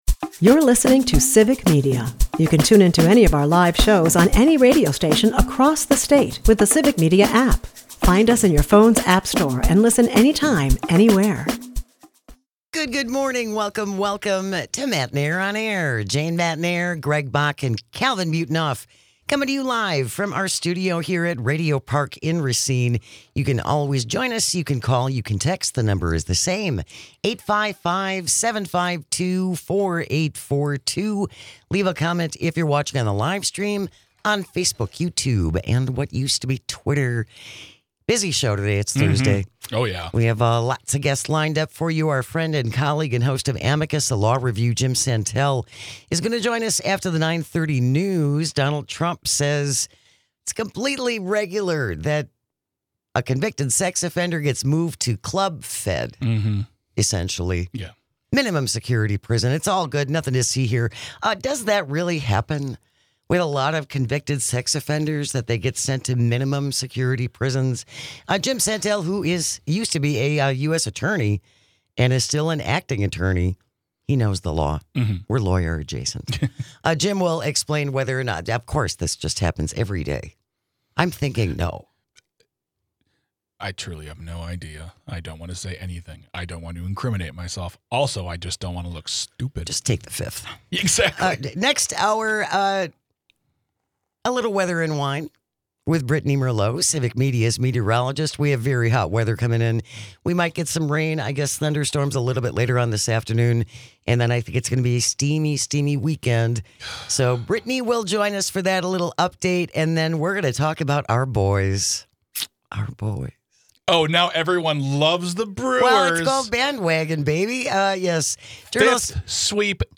Guests: Jim Santelle
Jim Santelle is a former US Attorney and host of Amicus on Civic Media , he joins us every Thursday to help break down all the law in the news.